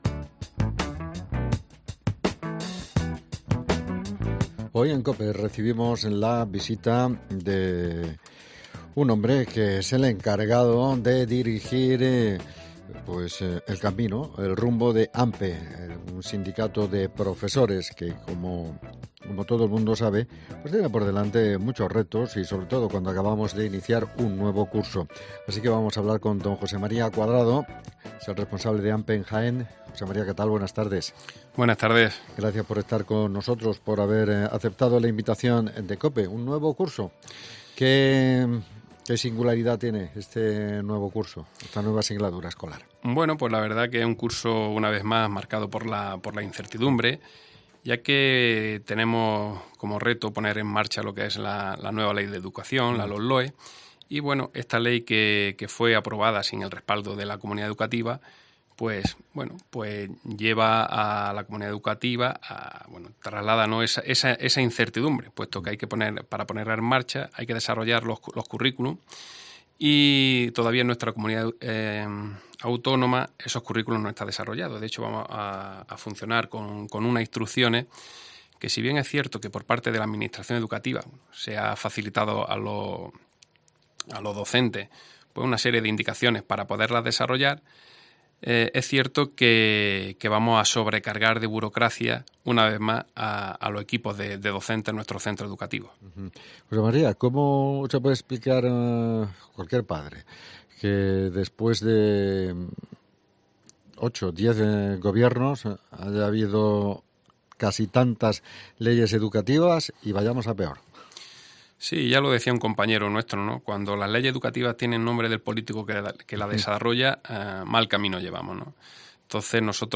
EDUCACIÓN